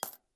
coin_coin_9.ogg